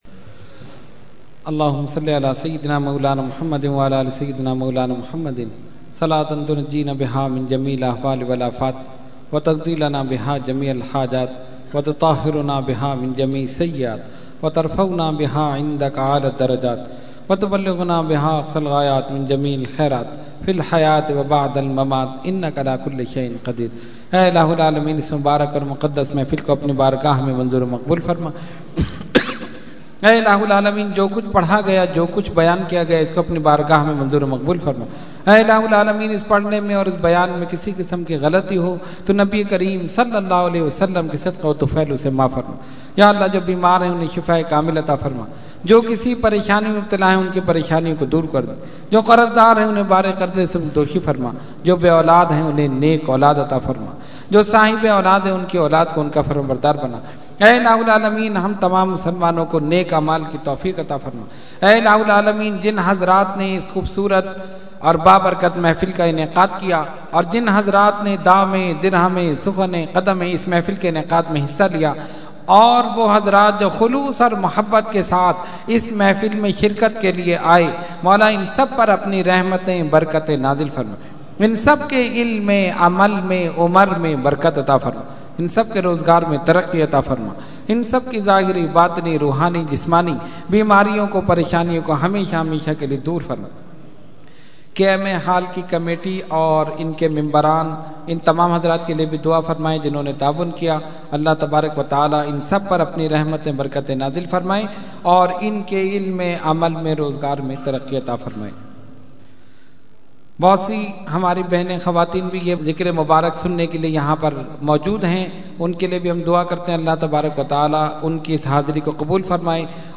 Dua – Mehfil 11veen KMA Hall 16 March 2012 – Dargah Alia Ashrafia Karachi Pakistan